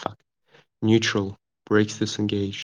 neutral-brakes-disengaged.wav